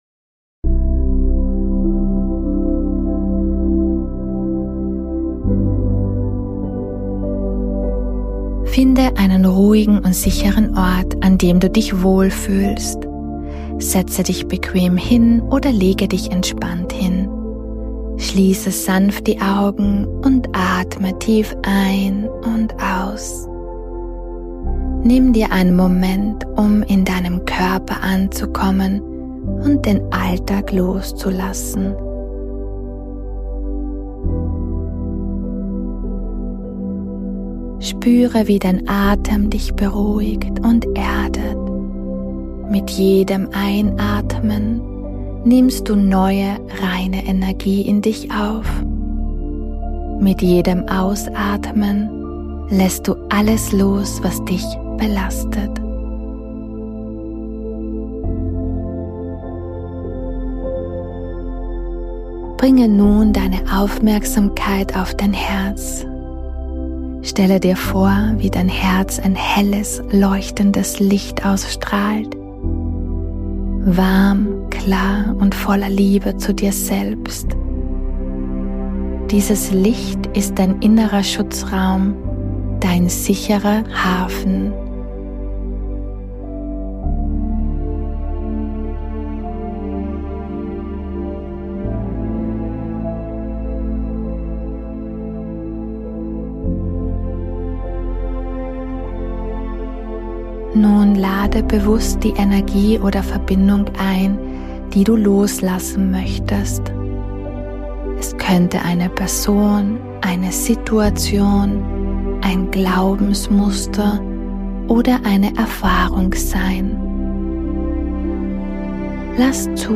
Diese Meditation kann dich dabei unterstützen, Personen, Erfahrungen, Situationen & Dinge loszulösen.